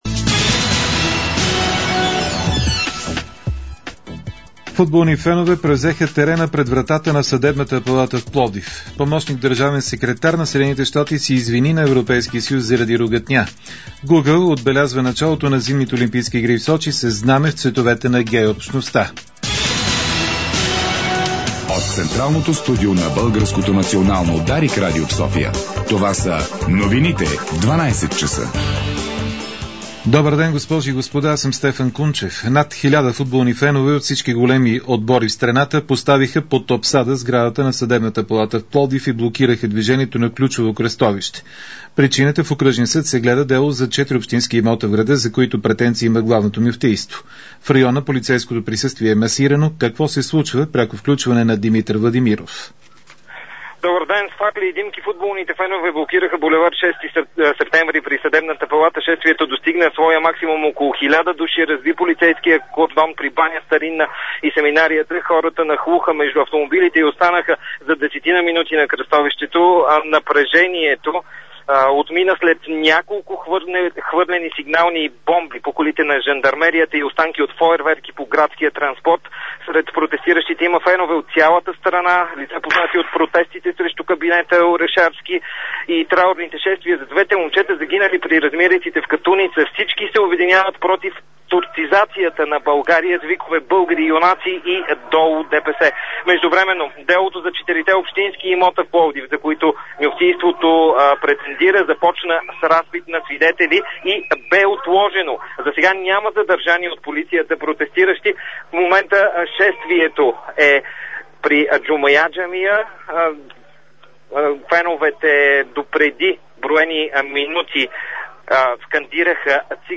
Обедна информационна емисия